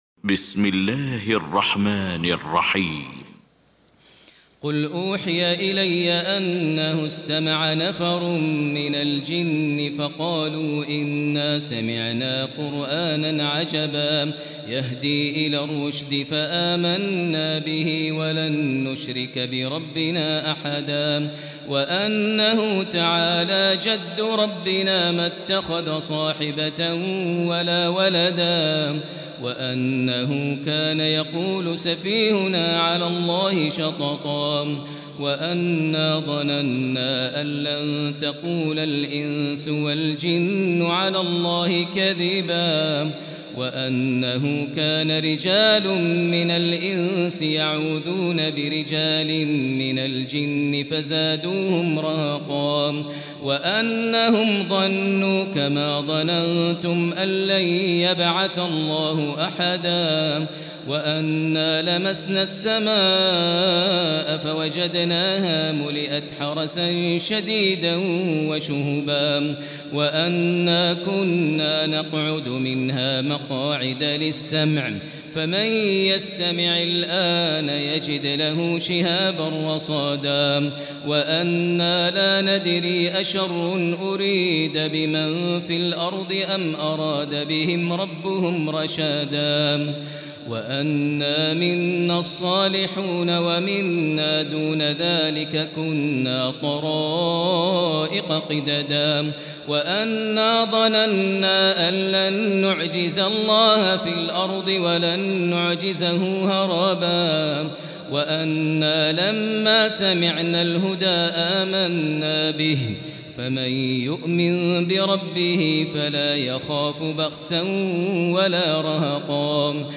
المكان: المسجد الحرام الشيخ: فضيلة الشيخ ماهر المعيقلي فضيلة الشيخ ماهر المعيقلي الجن